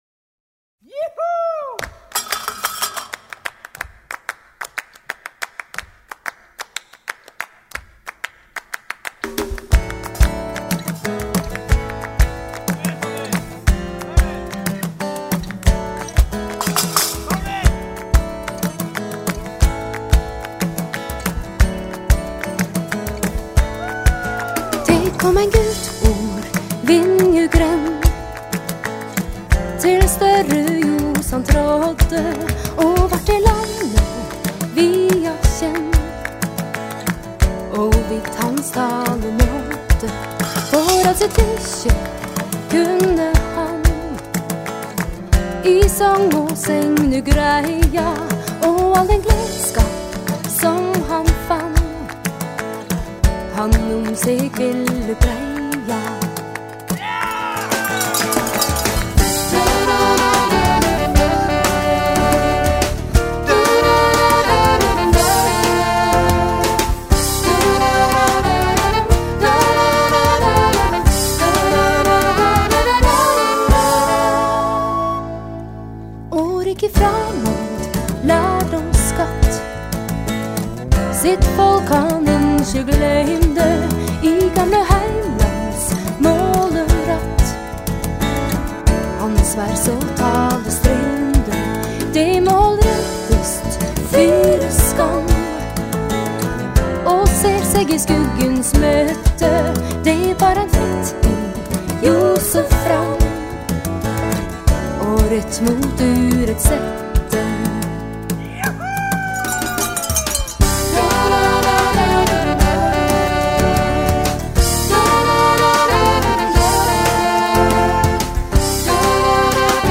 融合古典、摇滚、北欧乡村风、印度民族风、苏格兰曲风、柔情女声、吟游诗歌..等多元乐风
录音定位清楚、音质细腻清晰、音像深度及宽度精采可期
以挪威民谣为起点
透过人声、小提琴、萨克斯风、双簧管、贝斯、吉他、钢琴、打击乐器、印度笛、竖笛、扬琴、